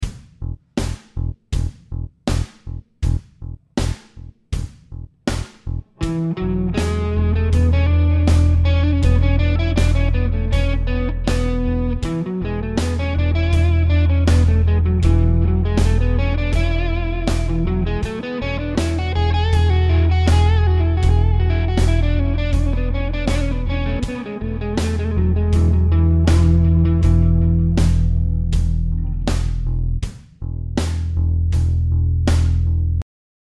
The bass is just playing the E note in all examples.
Mixolydian
When comparing to the major scale (ionian) it has a flattened 7th.
This has the same notes as A major and like before, playing the notes from the A major scale but using E as the focus point it will not sound like A major at all.